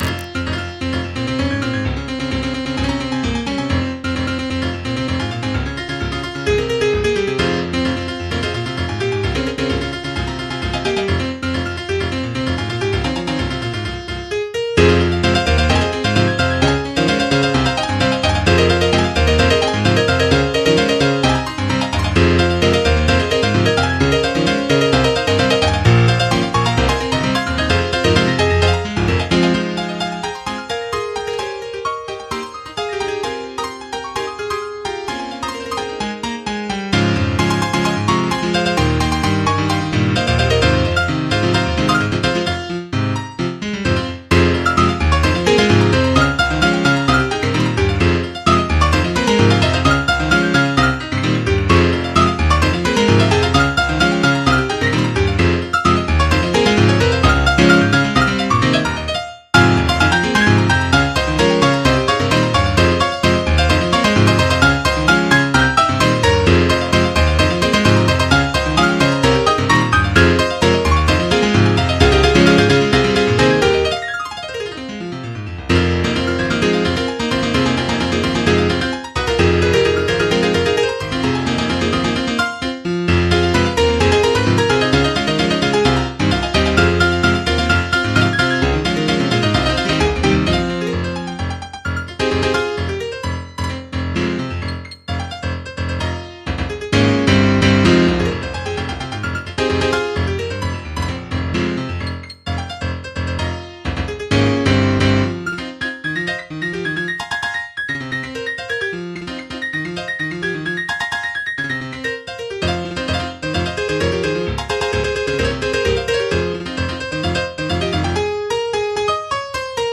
MIDI 27.03 KB MP3 (Converted) 2.37 MB MIDI-XML Sheet Music
hybrid trap, EDM, glitchop, bass house song
duet piano cover